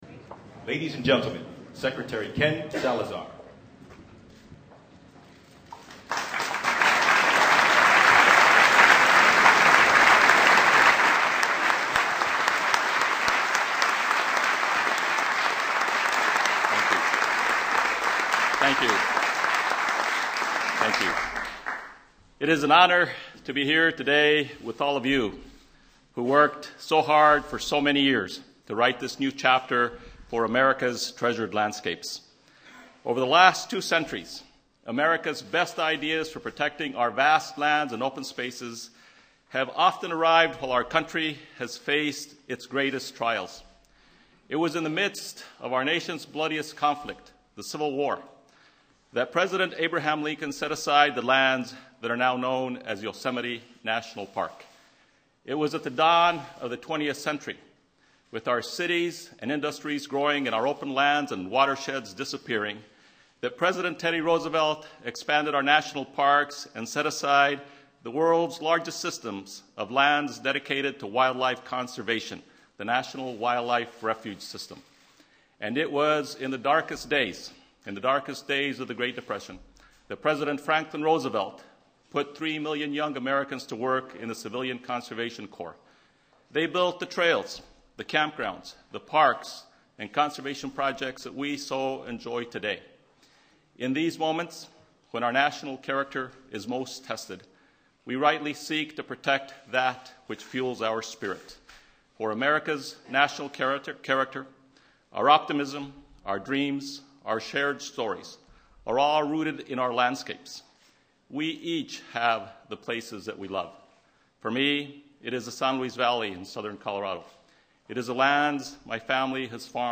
U.S. President Barack Obama signs the Omnibus Public Lands Management Act